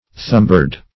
Thumbbird \Thumb"bird`\, n.